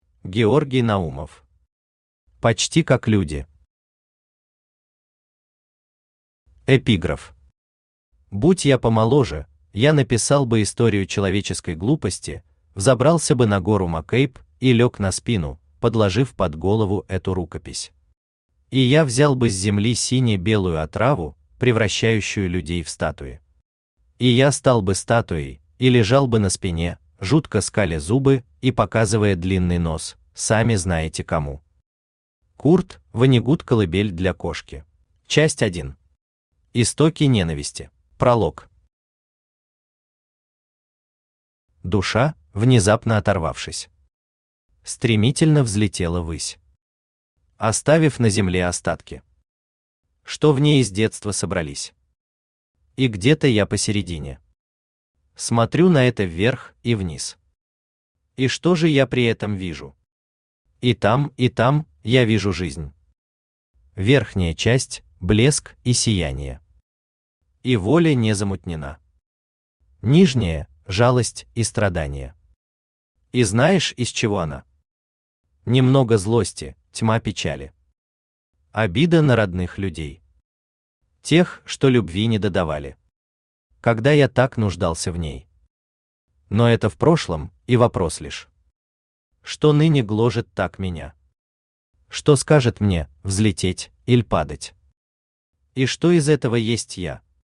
Aудиокнига Почти как люди Автор Георгий Васильевич Наумов Читает аудиокнигу Авточтец ЛитРес.